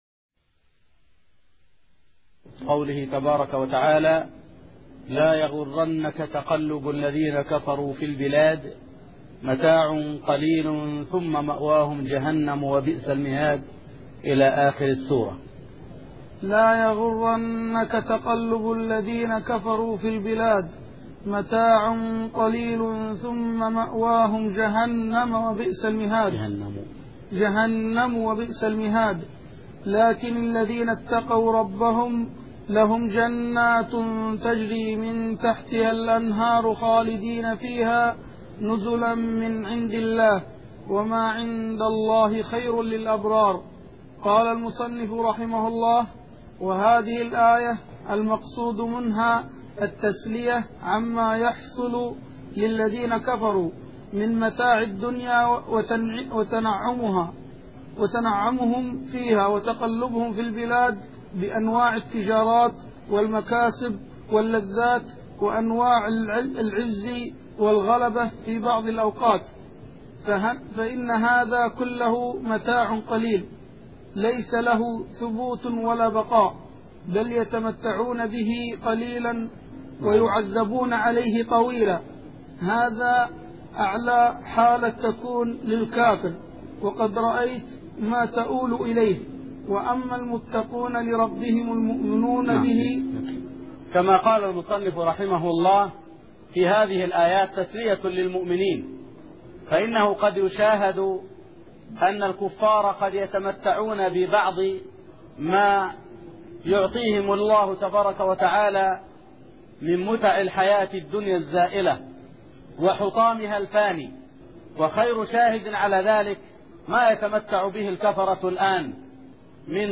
من دروس الحرم المدنى الشريف